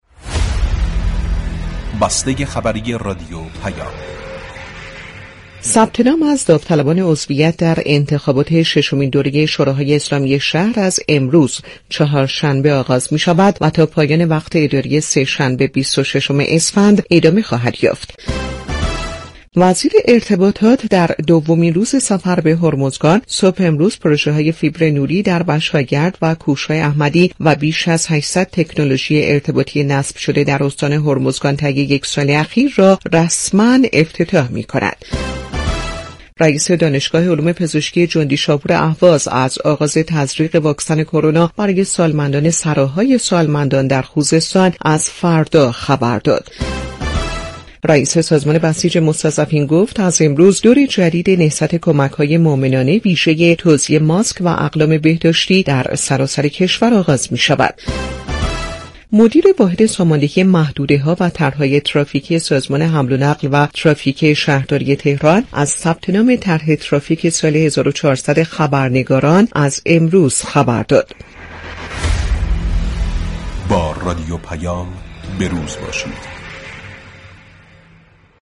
بسته خبری رادیو پیام